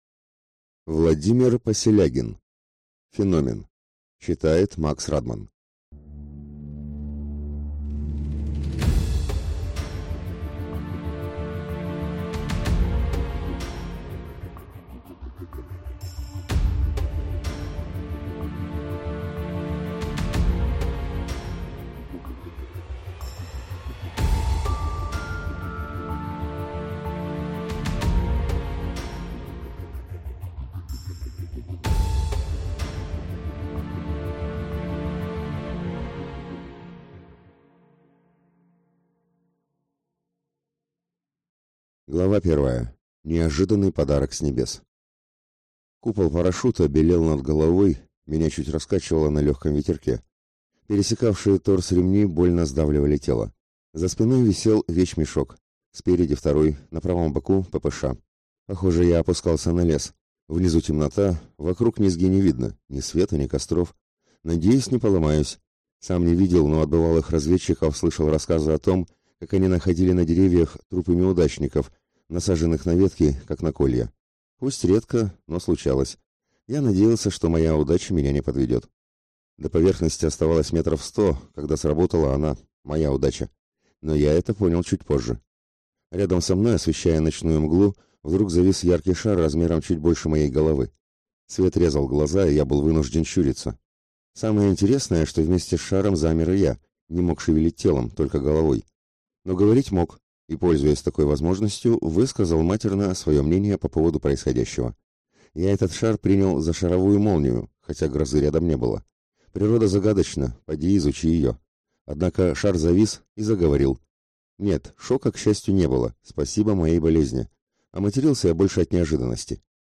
Аудиокнига Феномен | Библиотека аудиокниг